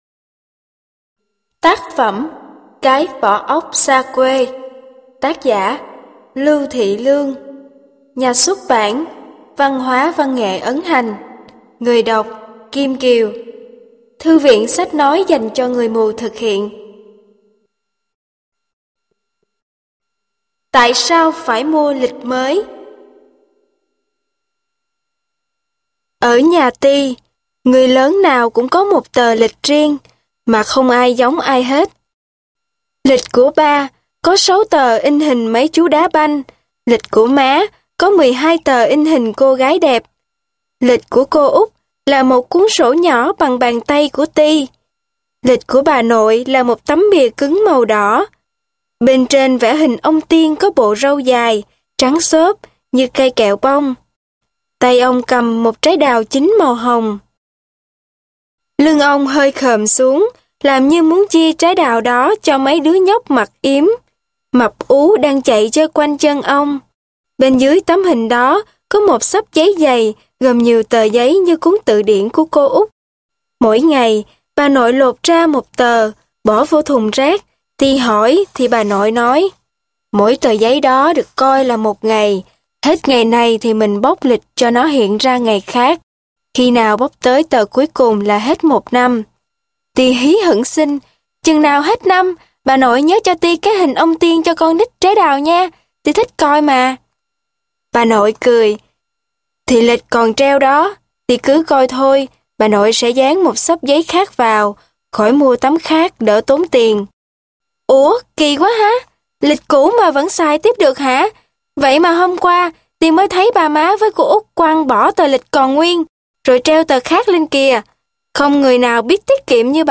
Sách nói Cái Vỏ Ốc Xa Quê - Lưu Thị Lương - Sách Nói Online Hay